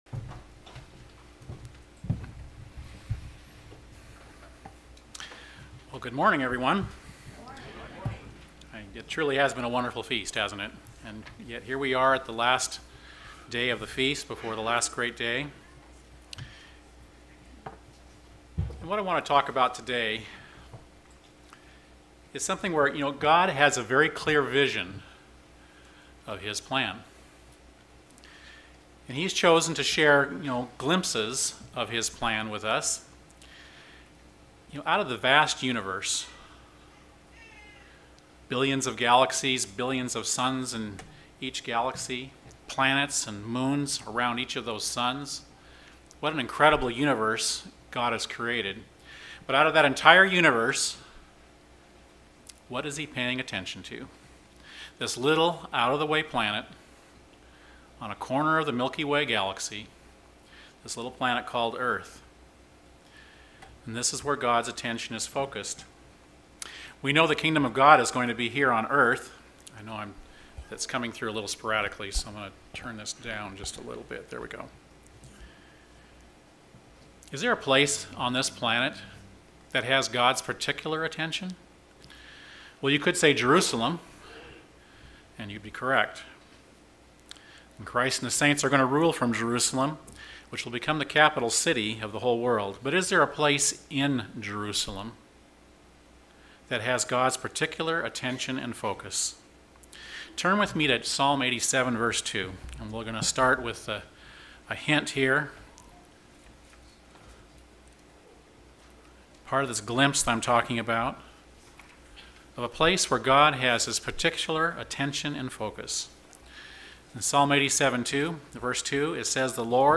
This sermon was given at the Osoyoos Lake, British Columbia 2018 Feast site.